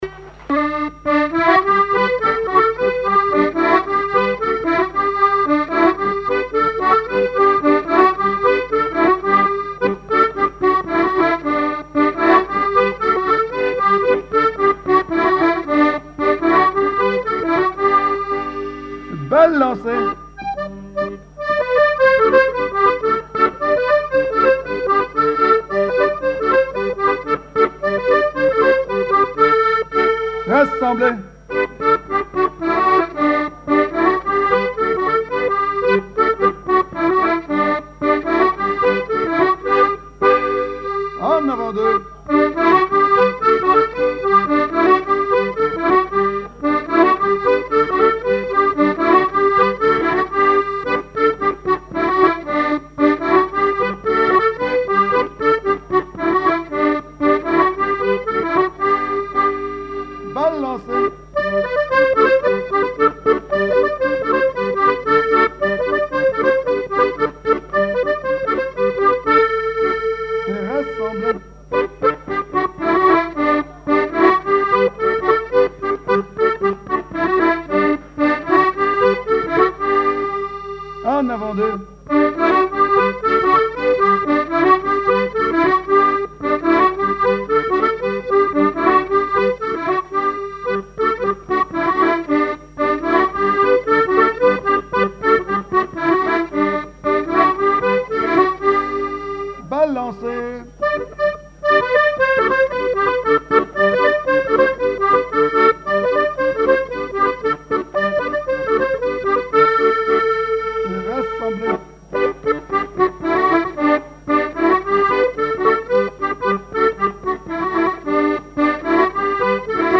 Chants brefs - A danser
danse : branle : avant-deux
collectif de musiciens pour une animation à Sigournais
Pièce musicale inédite